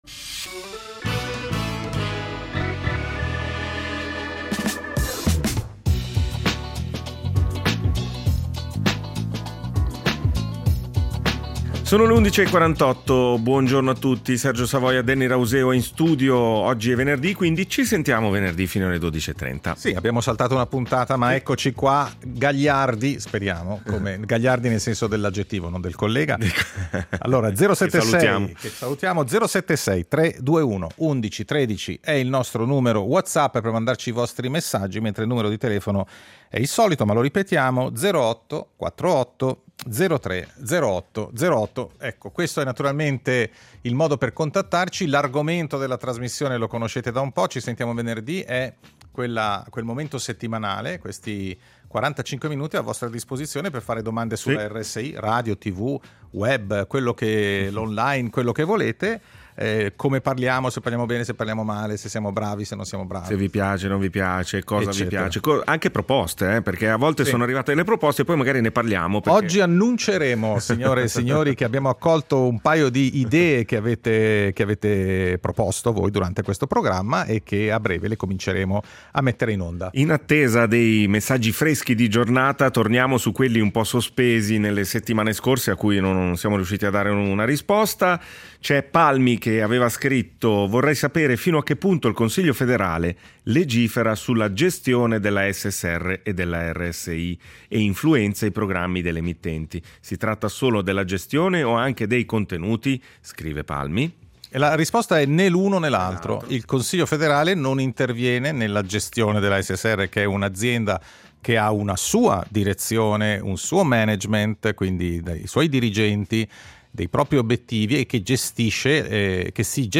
Ogni venerdì, dalle 11.45 alle 12.30, “Ci sentiamo venerdì” è il tavolo radiofonico dove ci si parla e ci si ascolta.